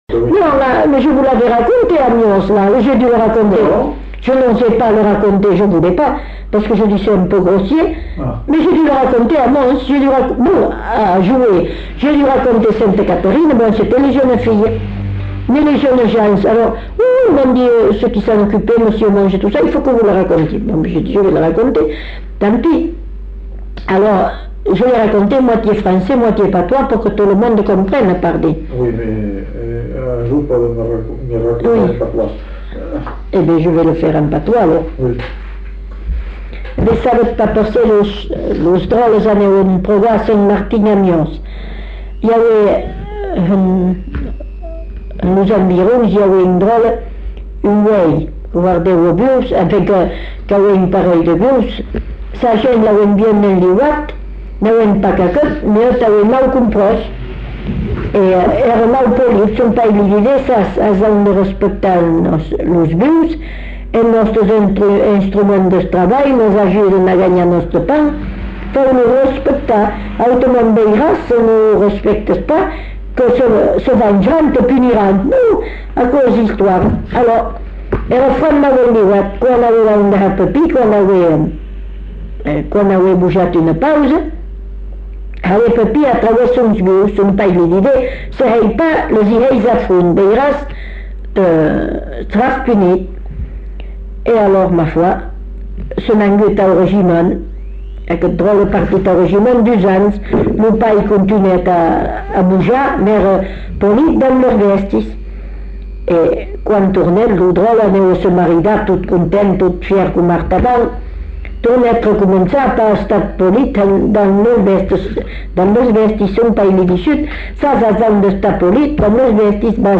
Aire culturelle : Bazadais
Lieu : Belin-Beliet
Genre : conte-légende-récit
Effectif : 1
Type de voix : voix de femme
Production du son : parlé